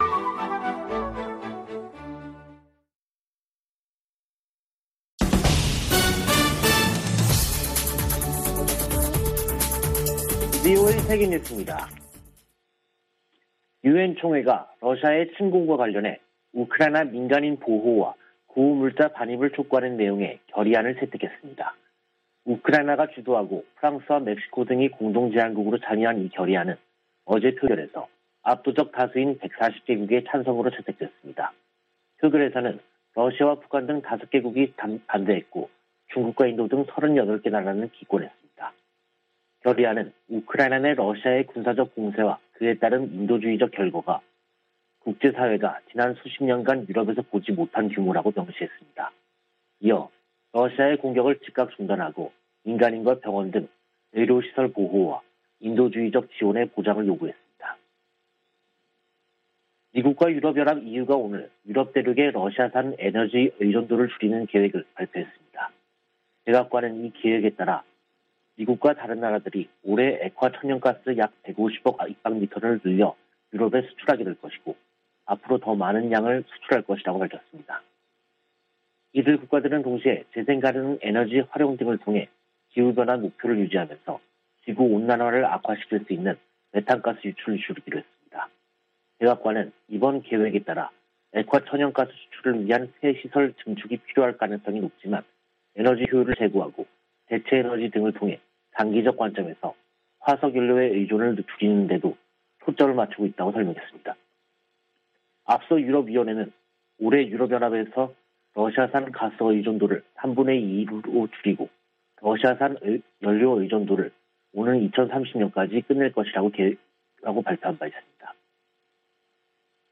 VOA 한국어 간판 뉴스 프로그램 '뉴스 투데이', 2022년 3월 25일 2부 방송입니다. 북한은 24일 발사한 탄도미사일이 신형 ICBM인 '화성-17형'이라며 시험발사에 성공했다고 밝혔습니다. 미국 정부는 북한이 ICBM으로 추정되는 장거리 탄도미사일을 발사한 데 강력한 규탄 입장을 밝혔습니다. 미국 정부가 북한 미사일 프로그램에 연관된 북한과 러시아의 개인과 회사들을 제재했습니다.